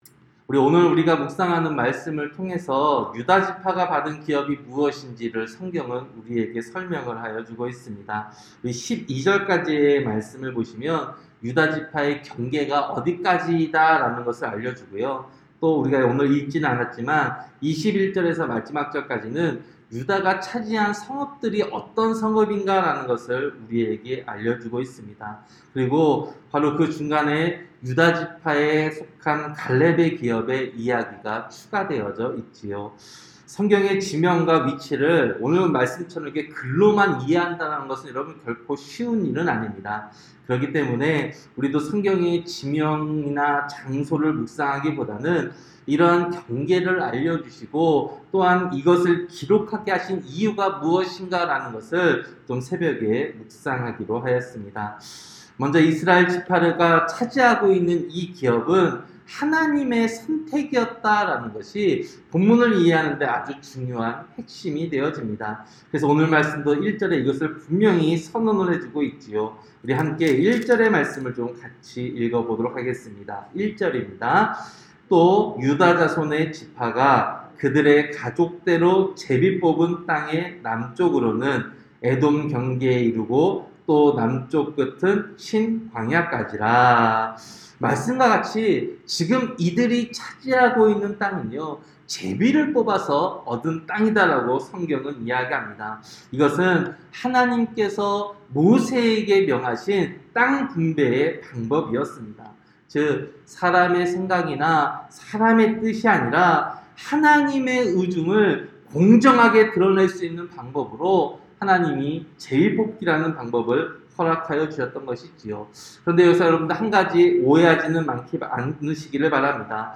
새벽기도-여호수아 15장